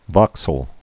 (vŏksəl)